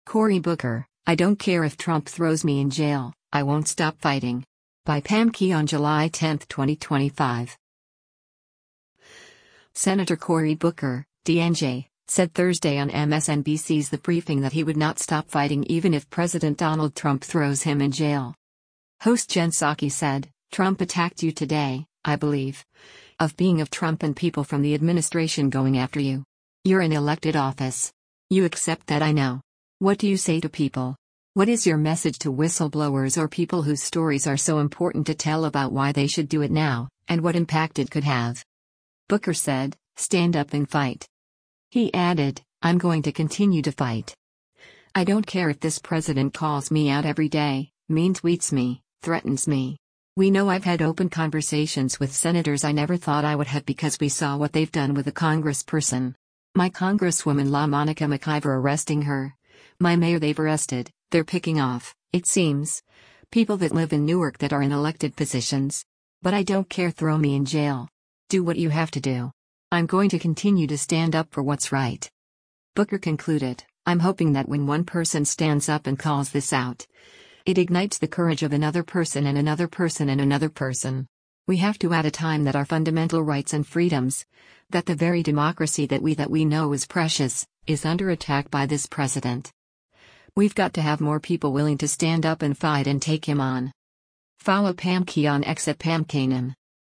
Senator Cory Booker (D-NJ) said Thursday on MSNBC’s “The Briefing” that he would not stop fighting even if President Donald Trump throws him in jail.